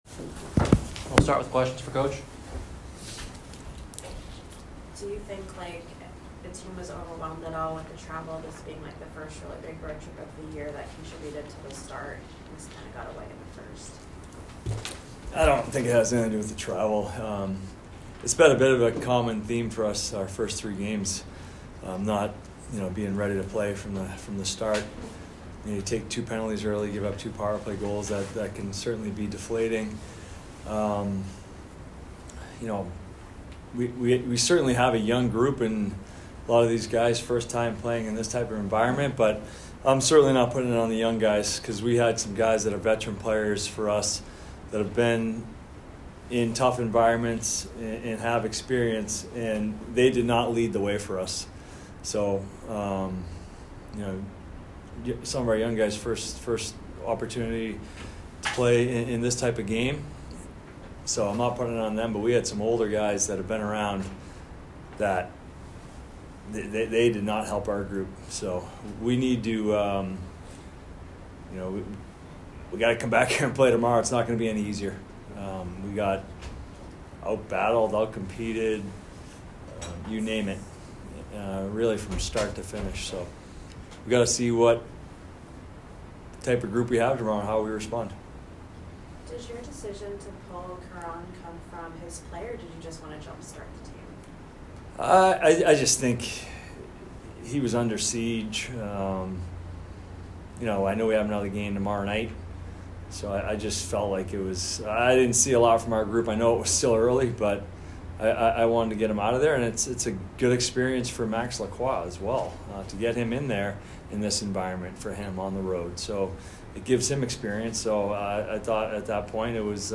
North Dakota Postgame Press Conference